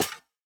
Monster Spawner step4 JE1 BE1.wav
Monster_Spawner_step4_JE1_BE1.wav